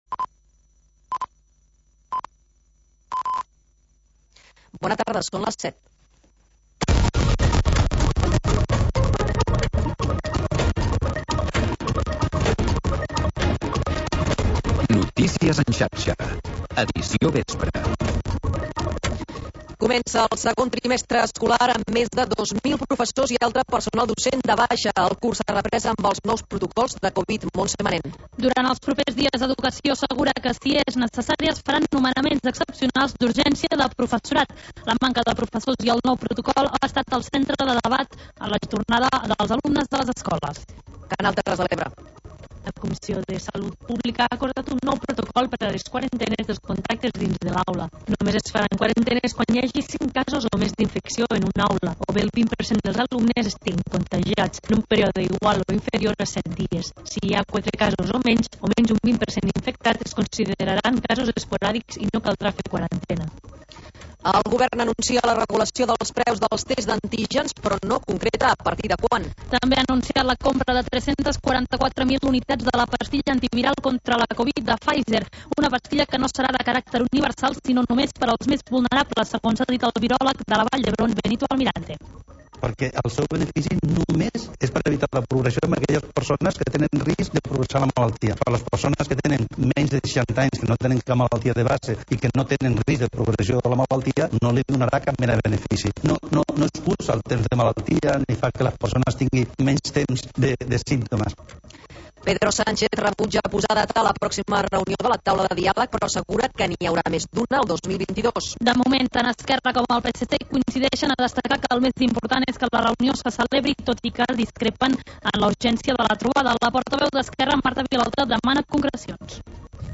Noticiari d'informació territorial